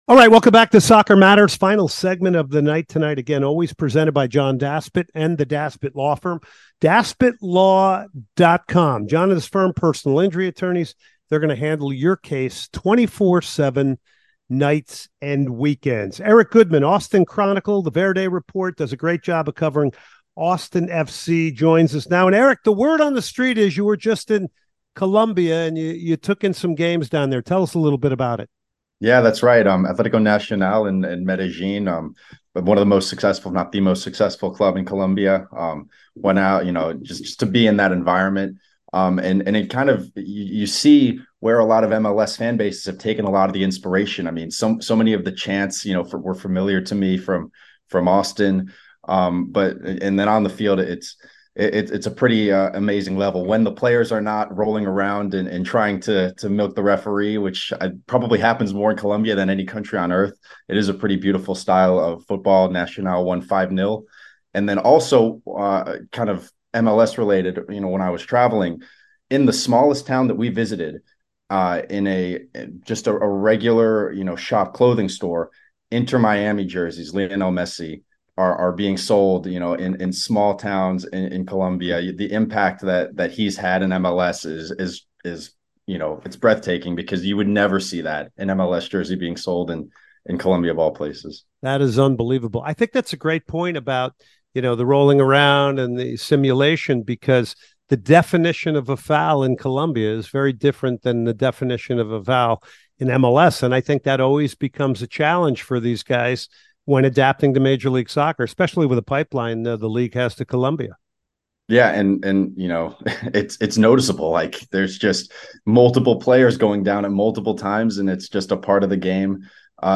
a long conversation